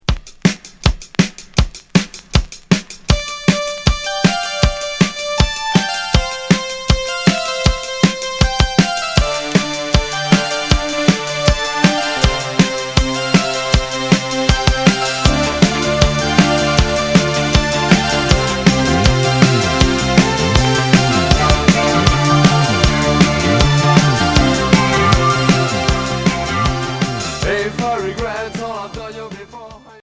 New wave